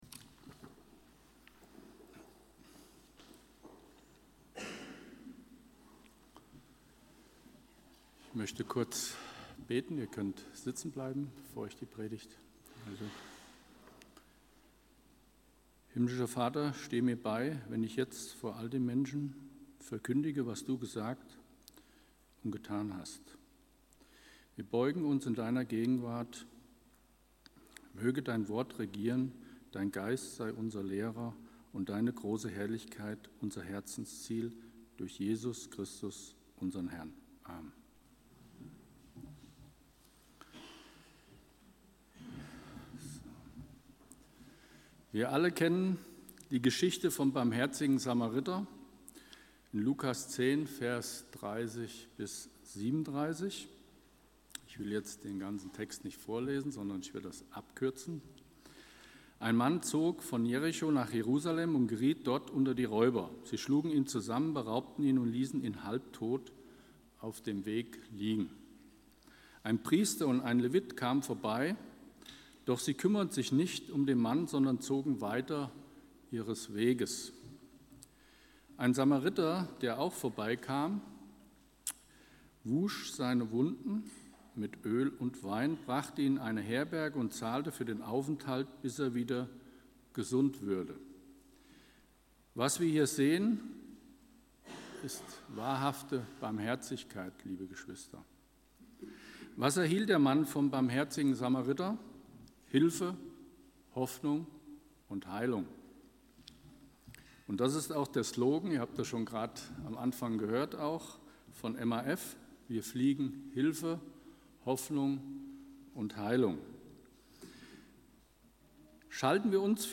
Die Predigt